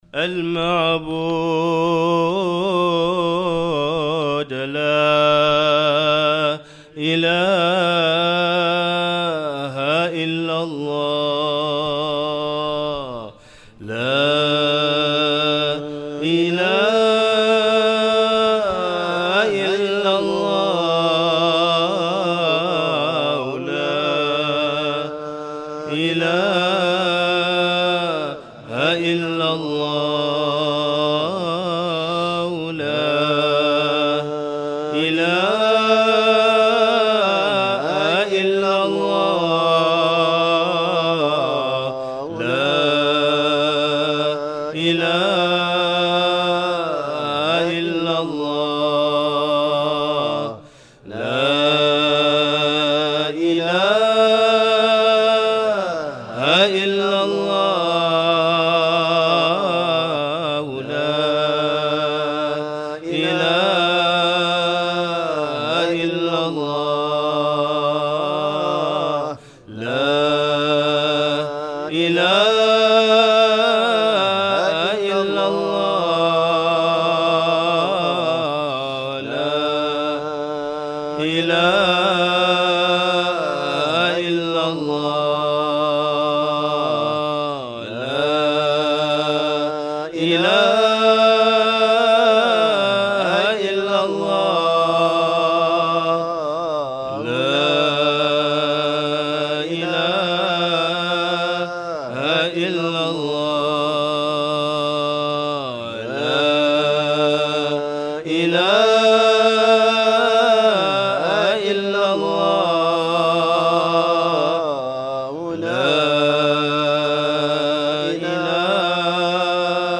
Ramadaan 2018 – Dhikr – Night 2
Ramadaan-2018-Dhikr-Night-2.mp3